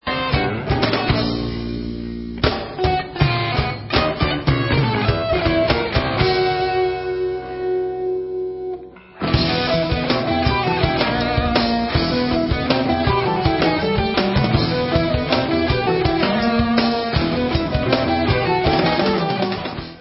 Recorded live november 1994